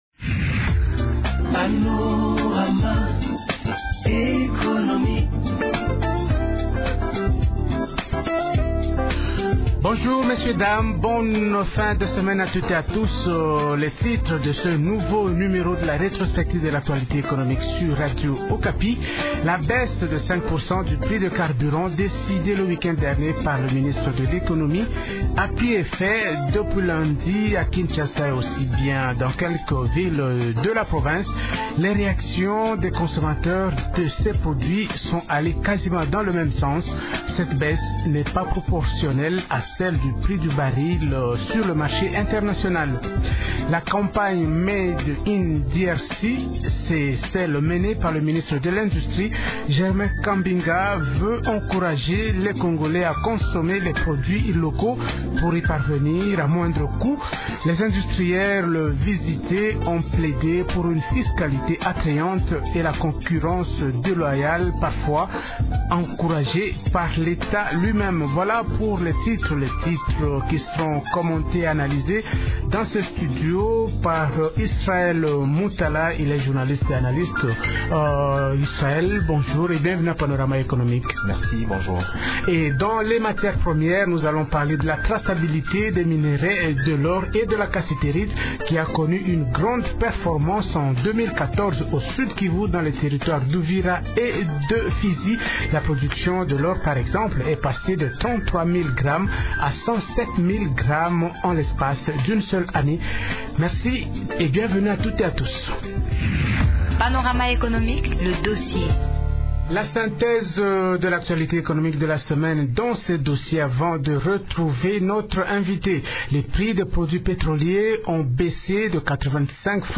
Ce magazine hebdomadaire fait également allusion aux réactions des automobilistes sur la baisse du prix de carburant à la pompe.